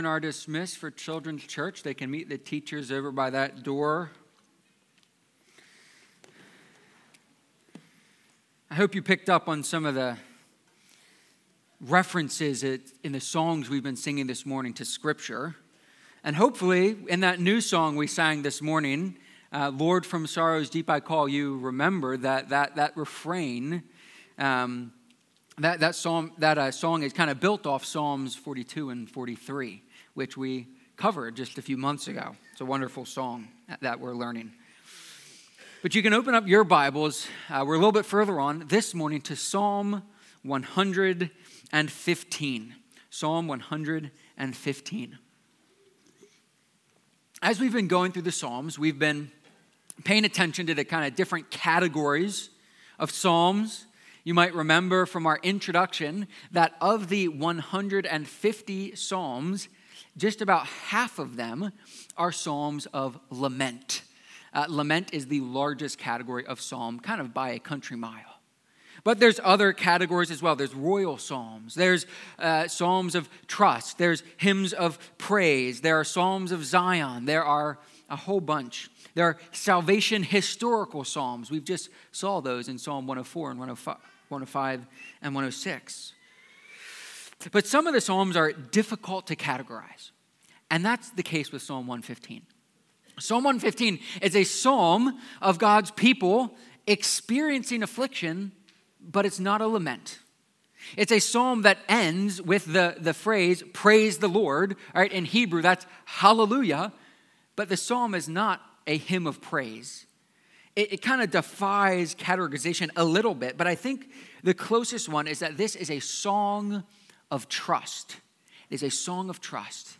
Psalm-115-sermon.mp3